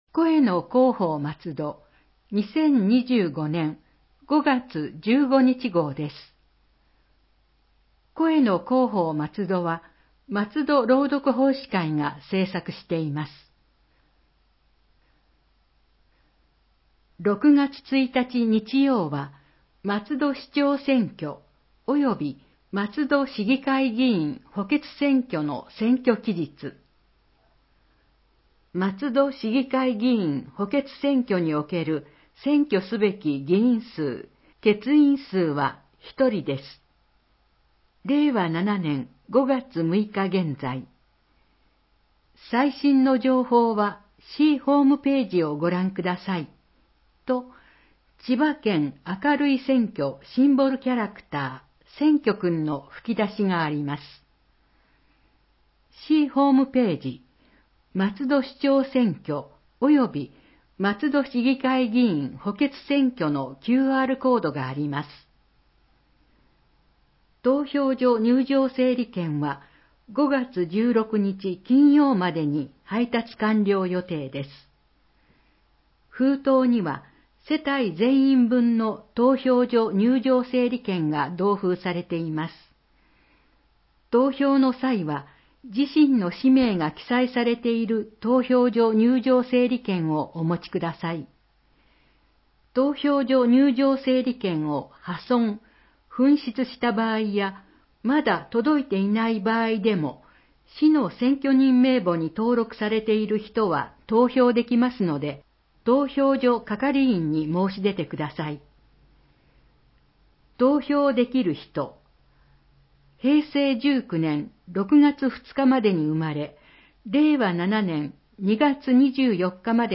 松戸朗読奉仕会のご協力で、広報まつどの音声版を公開しています。